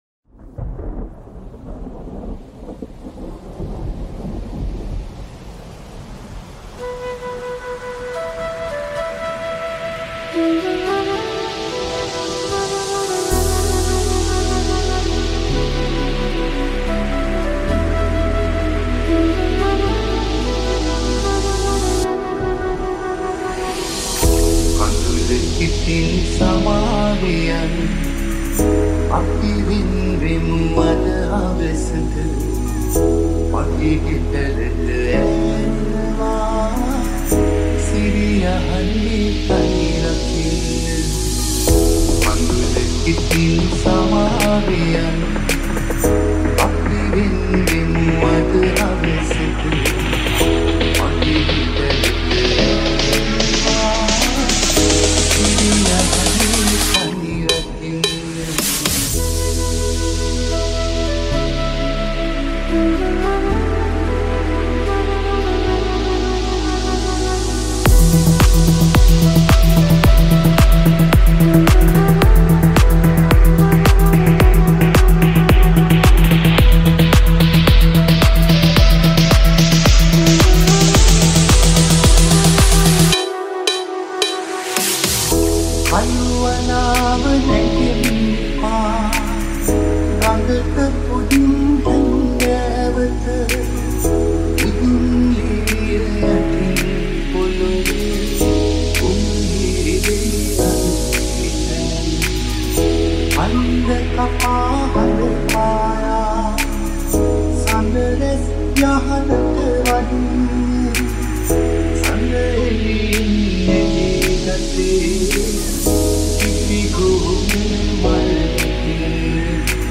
Sawanatha Remix New Song